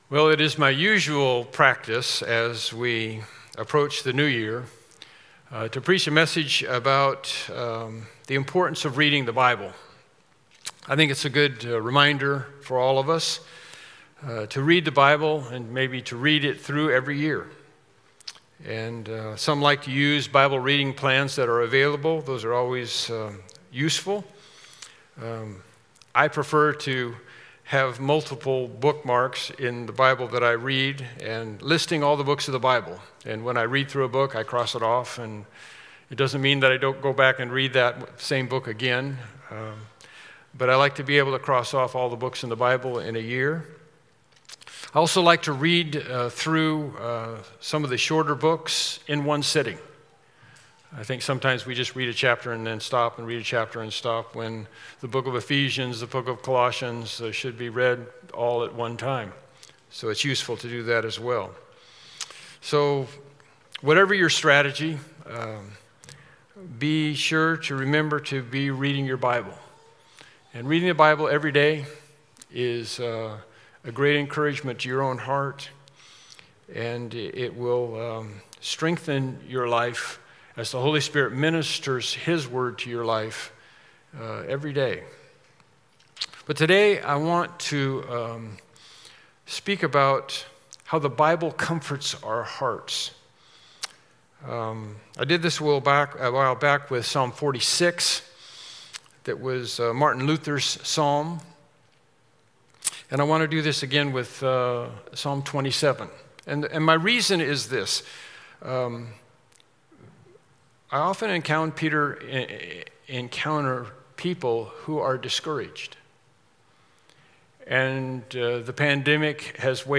Psalm 27:1-14 Service Type: Morning Worship Service « Lesson 17